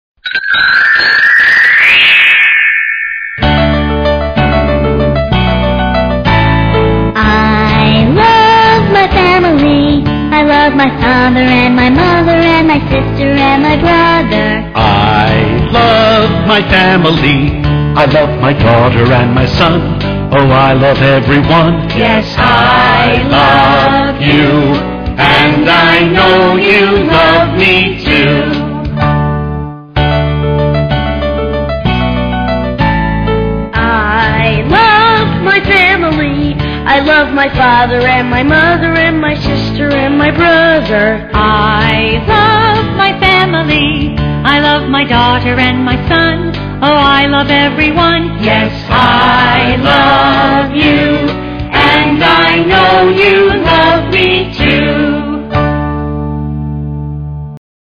在线英语听力室英语儿歌274首 第86期:I Love Little Pussy的听力文件下载,收录了274首发音地道纯正，音乐节奏活泼动人的英文儿歌，从小培养对英语的爱好，为以后萌娃学习更多的英语知识，打下坚实的基础。